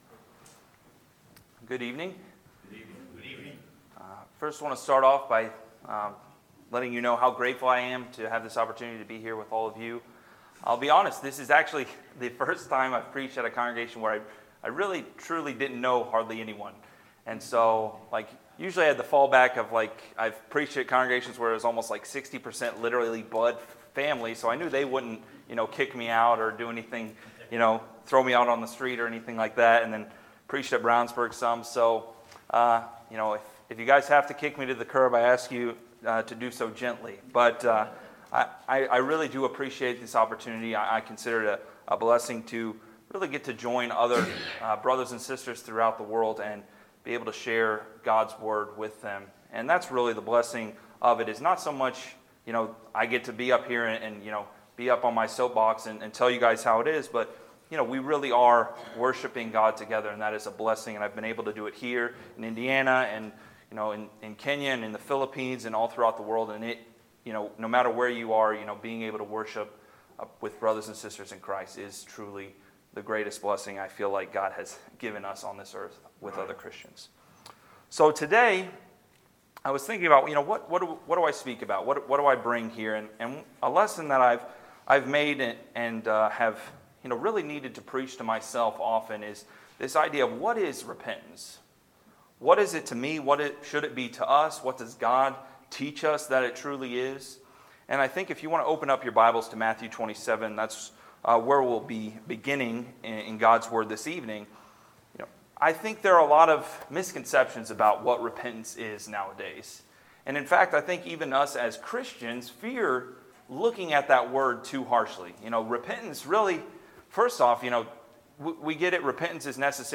Sermons, January 27, 2019